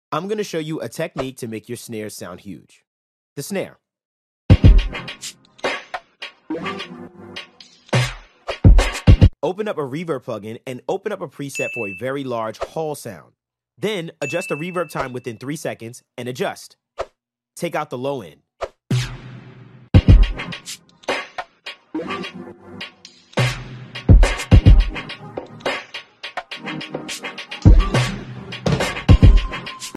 🥁 Make your snare sound sound effects free download